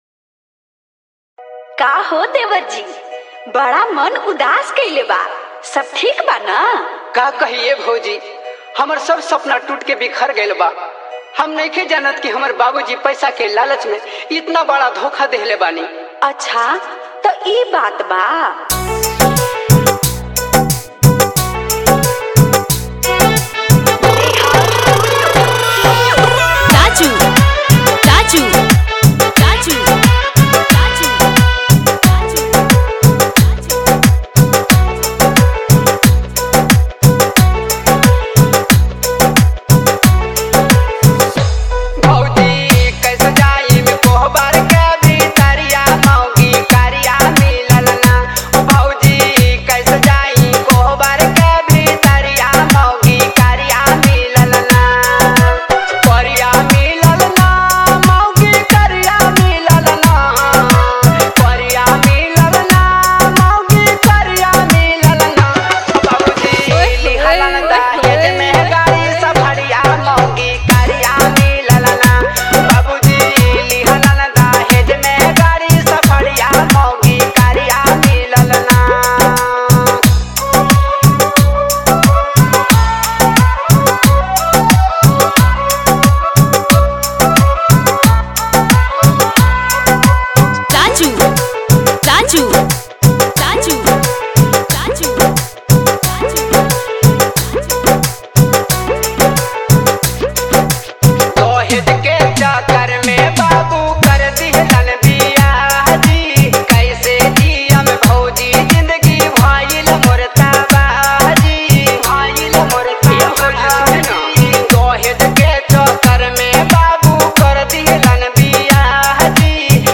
Bhojpuri All Dj Song